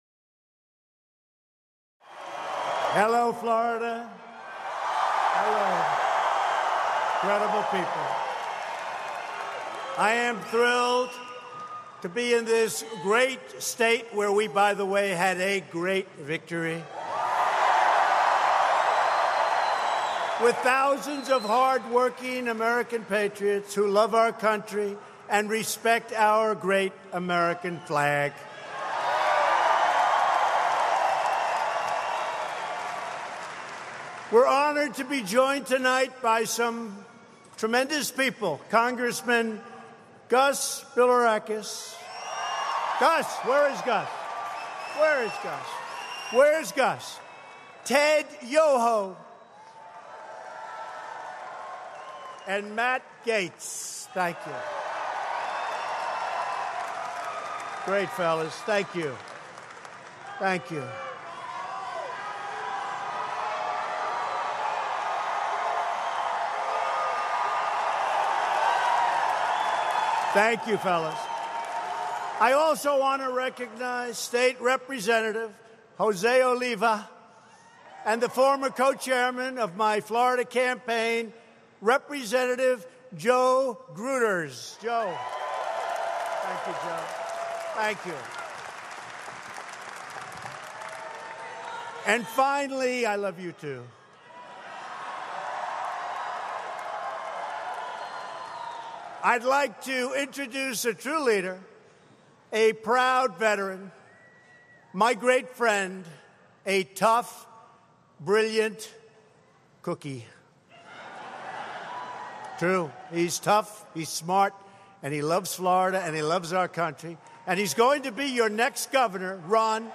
Trump talks about the state of the U.S. economy, foreign policy, and trade. DeSantis thanks Trump for his support and says that if elected he will enact conservative policies that will help Florida's economy grow. Held in Tampa, Florida.